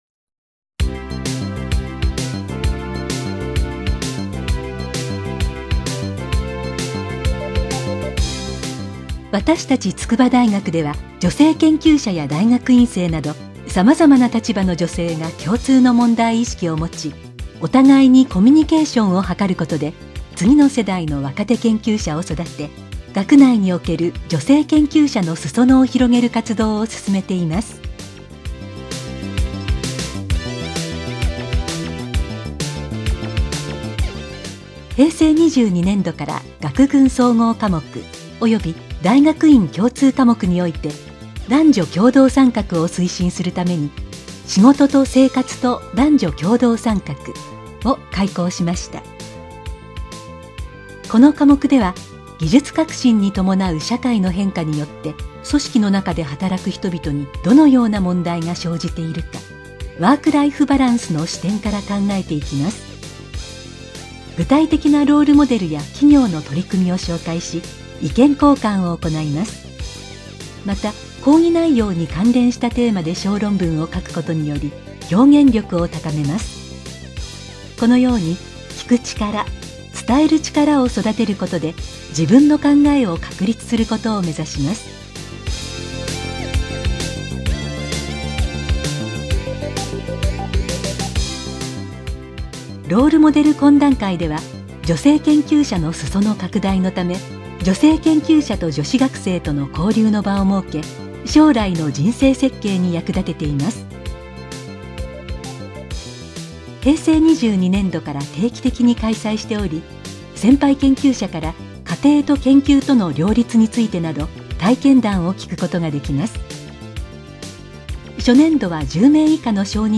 2.BGMとナレーション付きプラン
お客様が作成したパワーポイントをビデオ変換し、お客様が書いたナレーション原稿をプロの女性ナレ―ターが録音します。
ボイスサンプル　別のナレーターをご希望の場合は別途お見積りいたします。